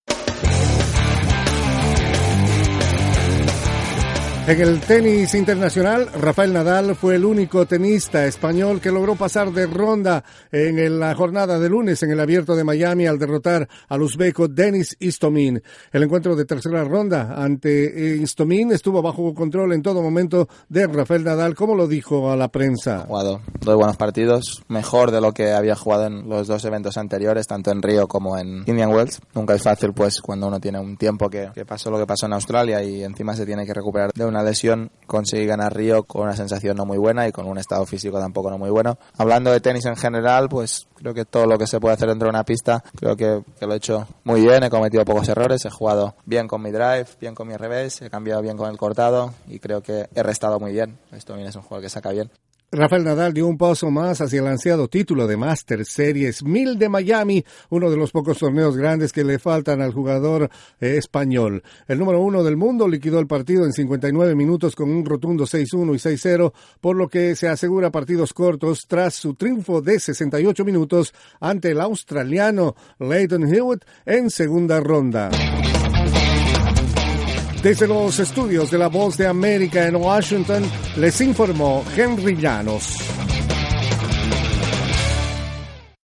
El tenista español Rafael Nadal, todavía manteniéndose como el número uno del mundo, paso a octavos de final en el Abierto de Miami Master 1.000. Informa